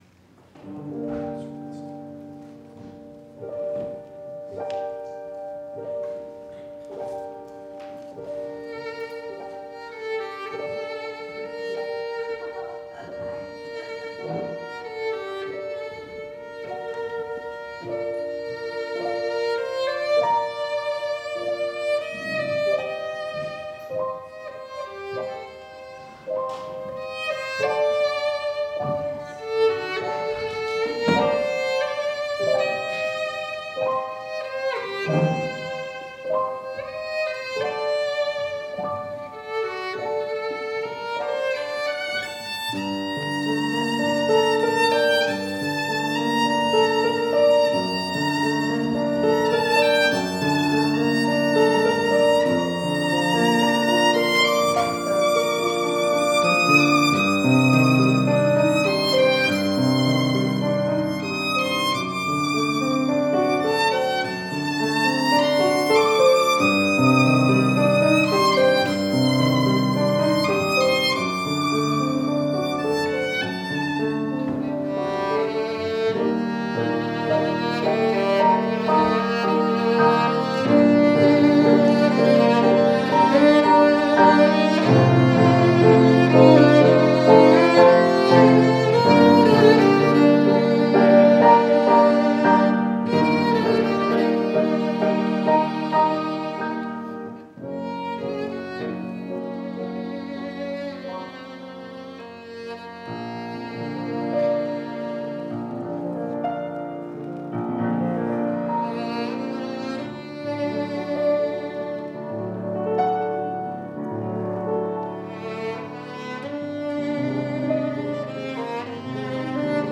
Instrumentation: Violin, Piano